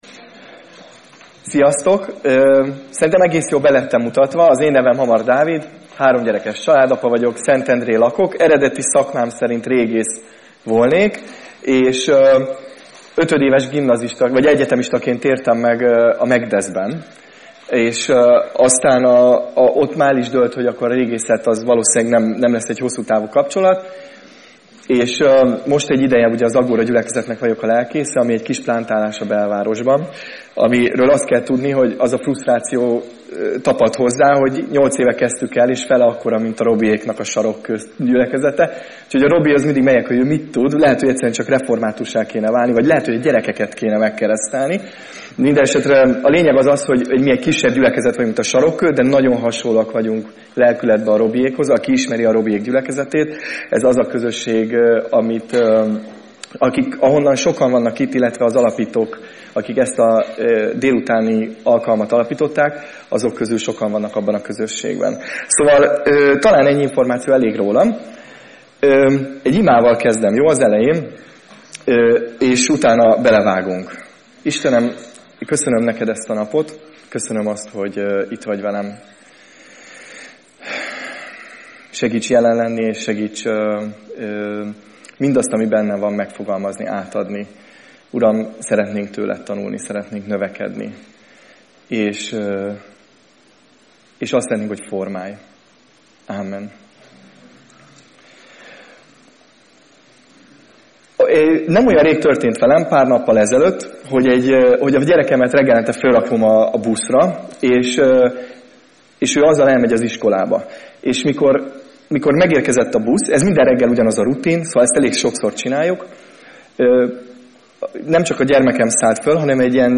Előadások